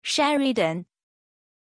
Pronunciation of Sheridan
pronunciation-sheridan-zh.mp3